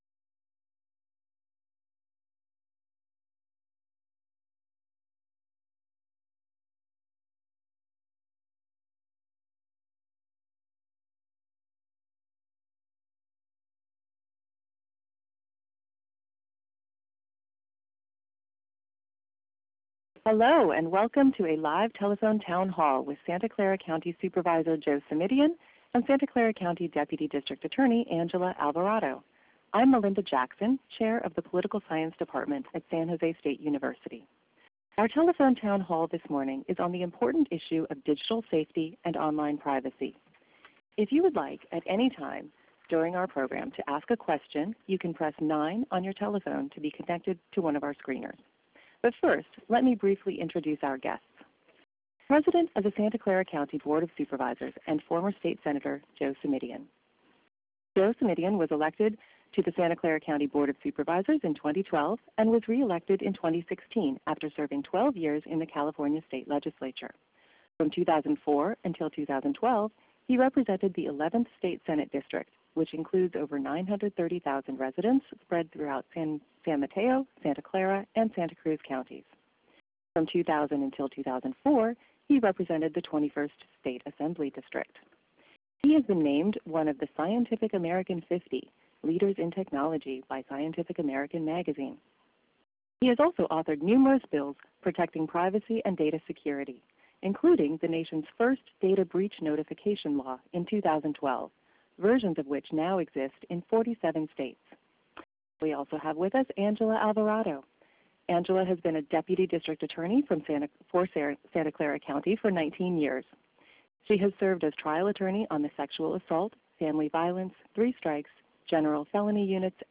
Telephone Town Halls